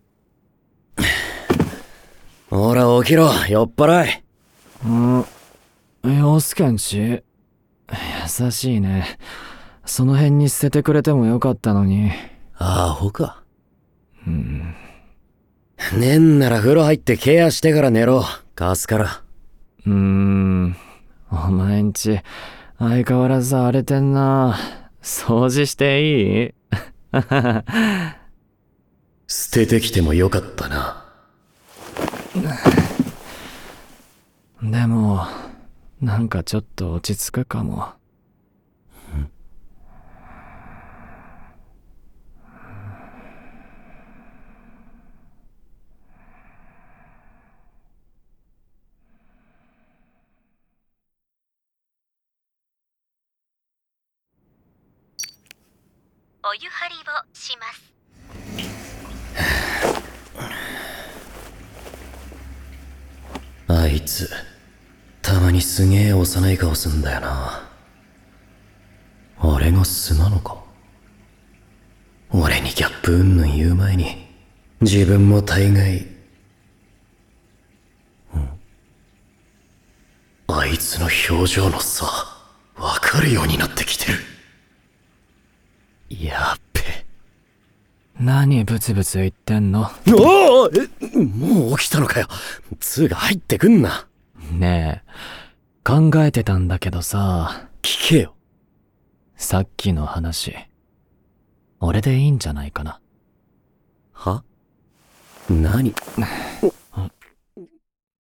ドラマCD「二日月に栖む」